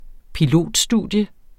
Udtale [ piˈloˀd- ]